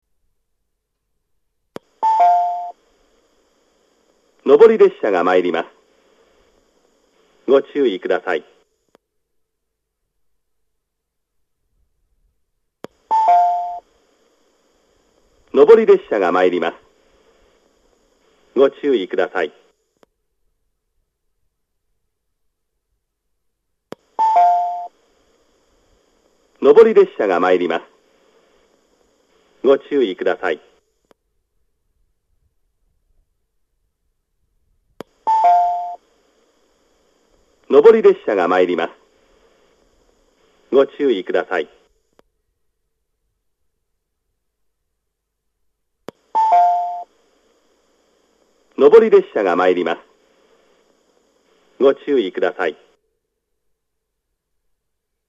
旧放送◆
従来の東北本線盛岡支社管内で標準的に使用されていたタイプの自動放送でした。
接近放送
男性による接近放送です。